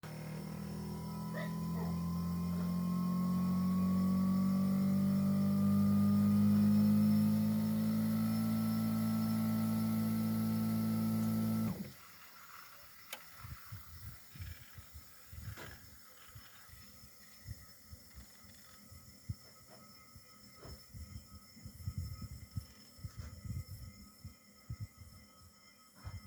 Mon groupe extérieur fait des clics clics mais ne se lance pas.
Voici un audio clim 1quand je remets l'électricité sur l'unité extérieure.
Dans le 1er mp3, on entend le compresseur augmenter en vitesse puis se couper brutalement.